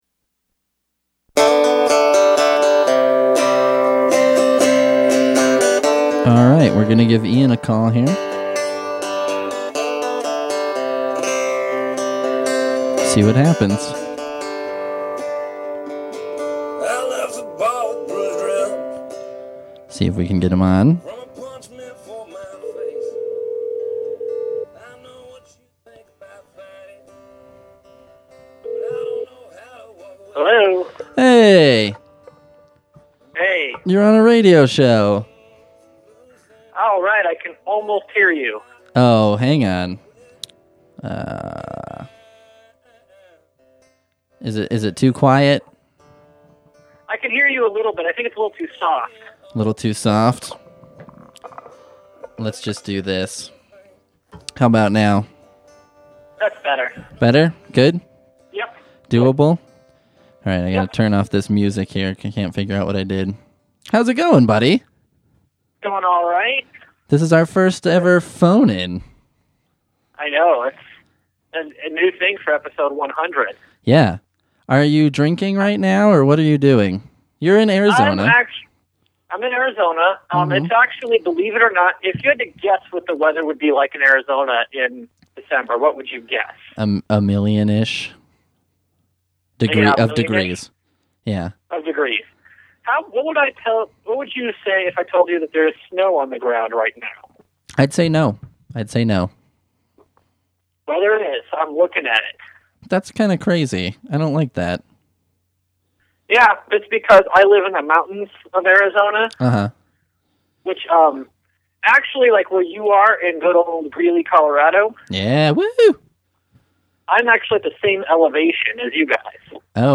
Our first ever phoner.